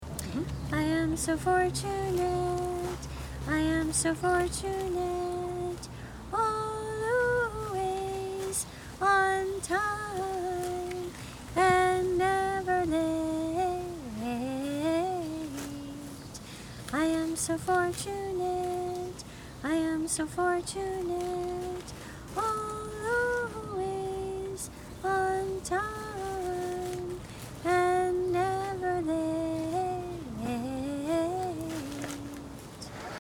See individual song practice recordings below each score.